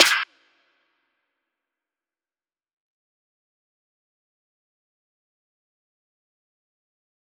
DMV3_Snare 11.wav